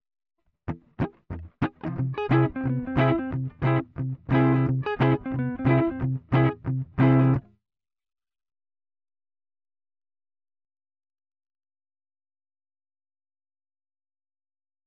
Augmented melodic fragment